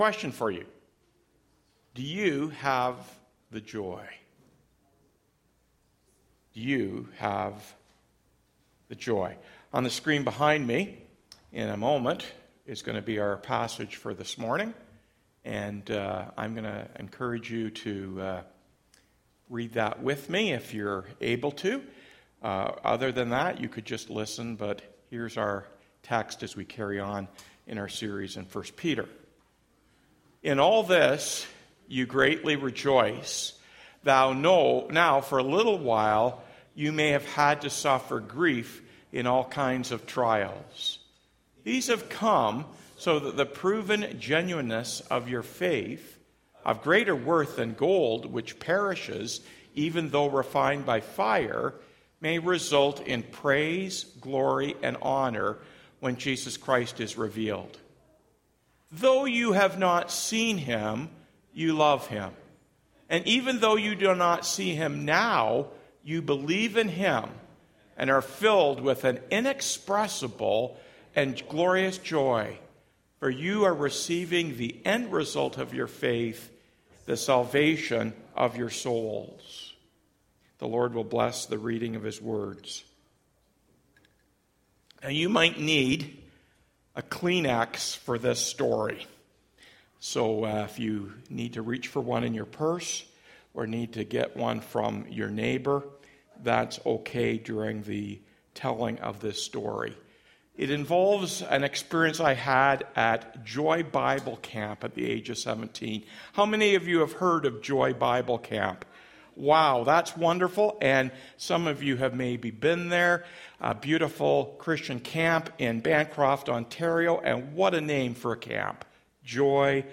Weekly Sermons - Byron Community Church